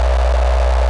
AmbLightBuzzA.wav